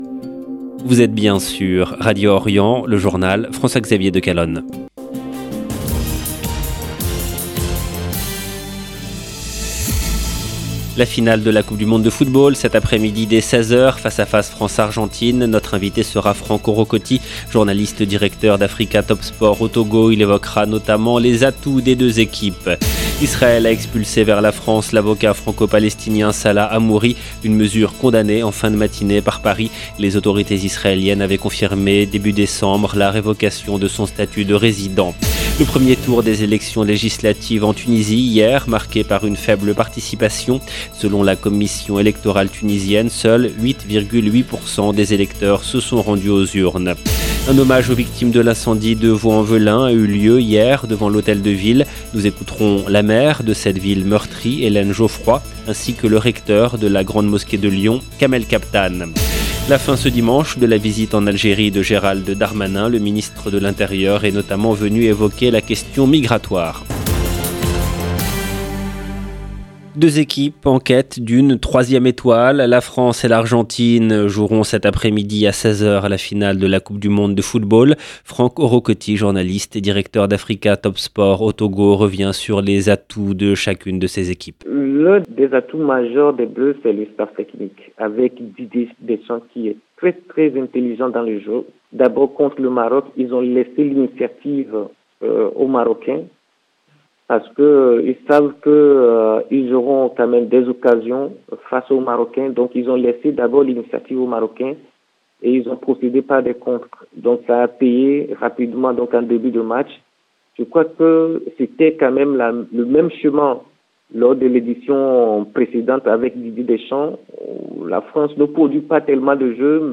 LE JOURNAL DE 12H EN LANGUE FRANCAISE DU 18/12/2022
Nous écouterons la maire de cette ville meurtrie, Hélène Geoffroy.